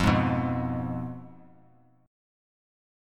Fdim chord